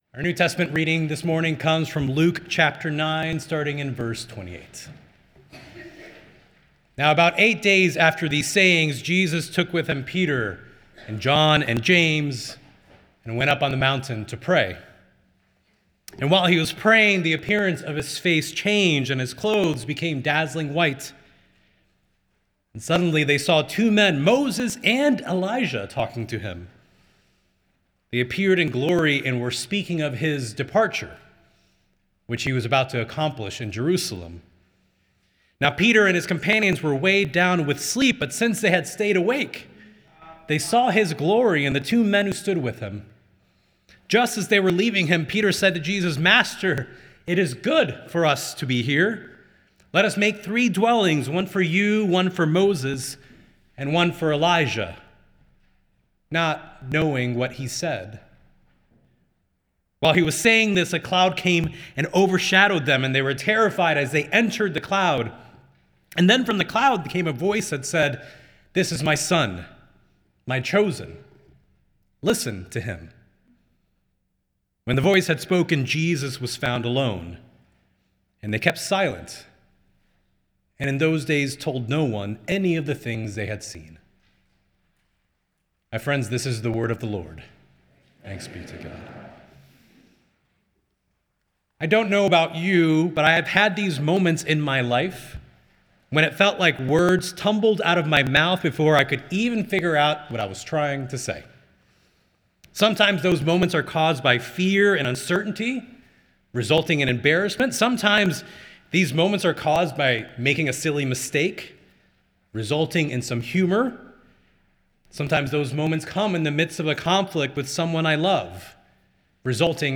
Nassau Presbyterian Church Sermon Journal Not Knowing